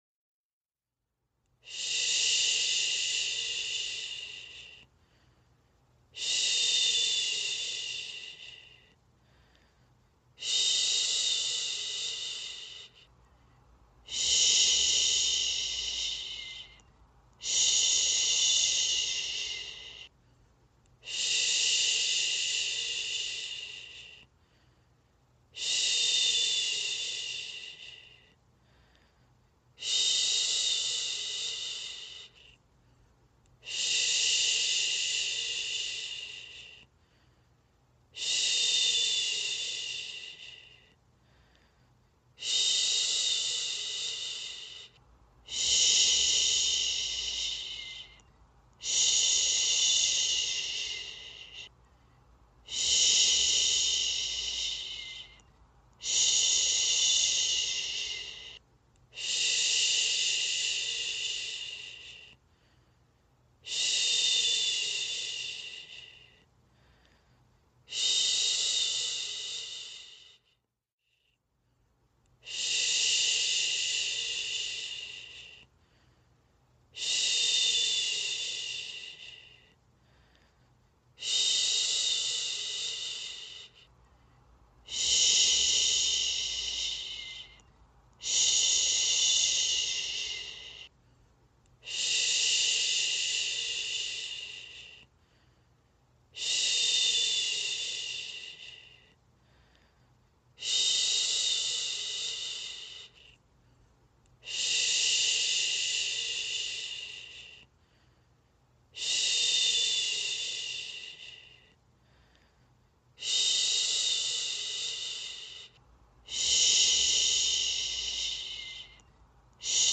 Shhh Baby Sleeping mp3
Hiệu ứng âm thanh Shhh (Baby Sleeping sound effect) mô phỏng tiếng "suỵt" nhẹ nhàng từ môi người, thường được dùng để dỗ dành hoặc giữ không gian yên tĩnh cho trẻ nhỏ. Đặc tính của âm thanh này là sự mềm mại, âm sắc gió (white noise) và nhịp điệu đều đặn, giúp tạo ra một bầu không khí bình yên, an toàn và cực kỳ thư giãn.